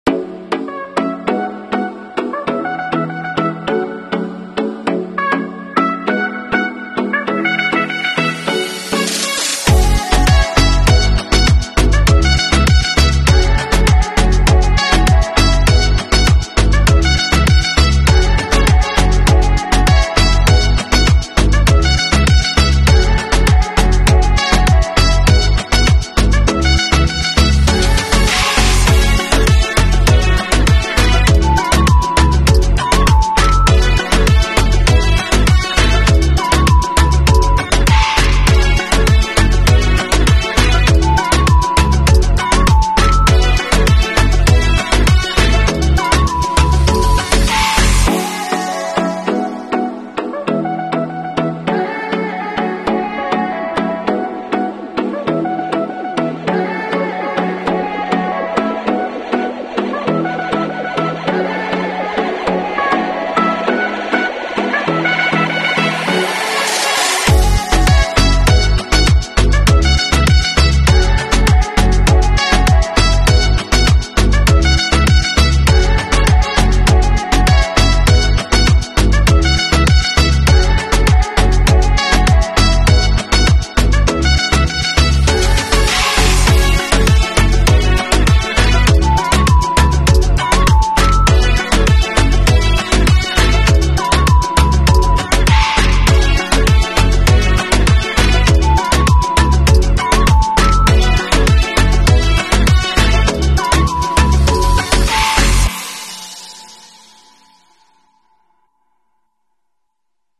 Great bass requires serious power. sound effects free download
The Kicker's KXA2400.1 subwoofer amplifier gives great bass, powering these Kicker L7 15" subs for this Jeep Grand Cherokee.